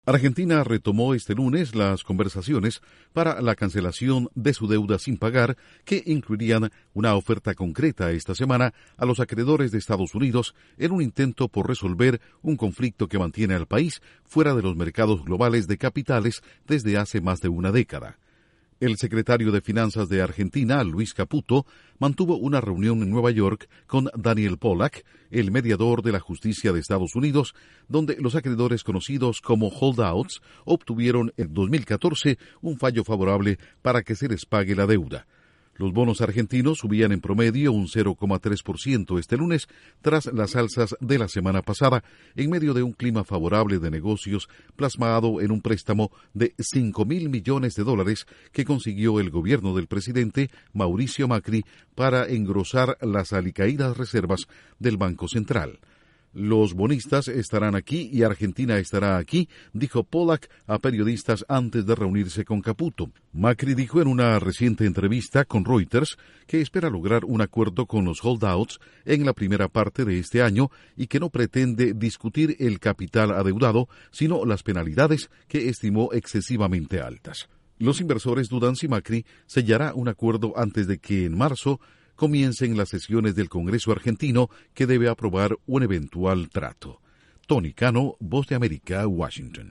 Argentina inicia conversaciones con Estados Unidos para cancelar su deuda con acreedores estadounidenses. Informa desde la Voz de América en Washington